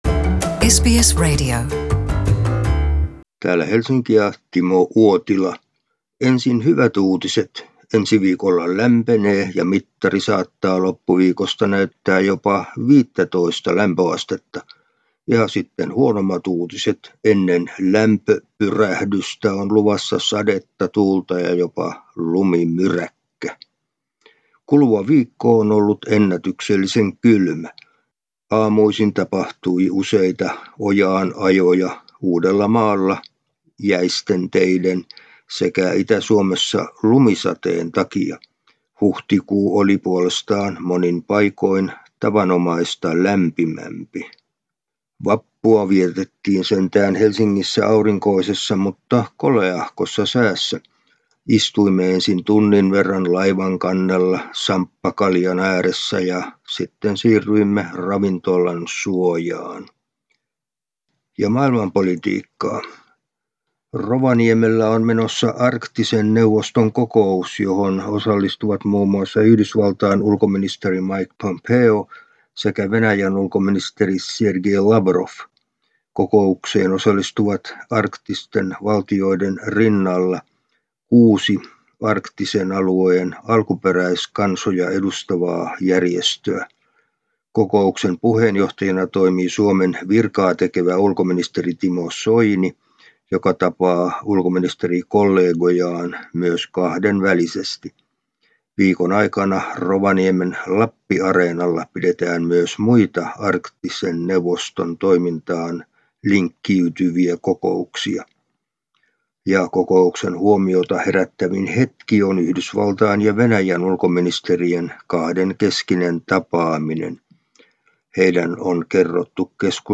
Suomen ajankohtaisraportti